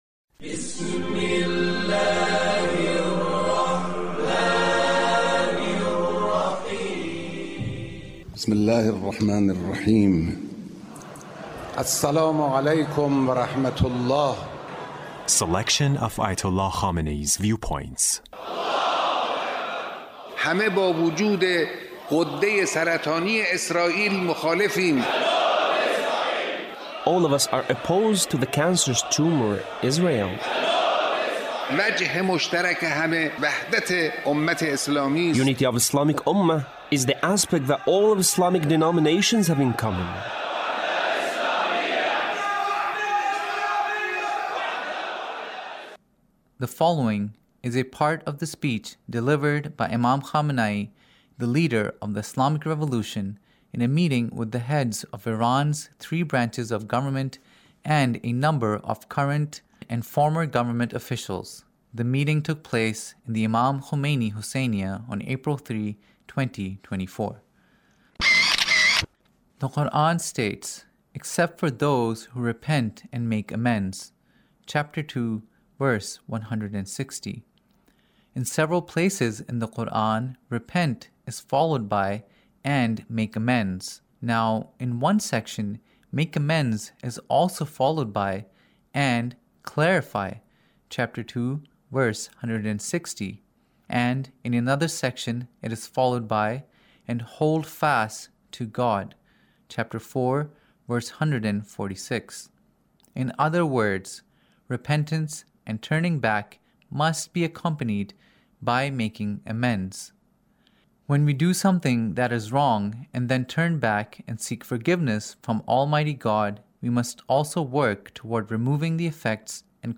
Leader's Speech in a Meeting with the Three Branches of Government Repentance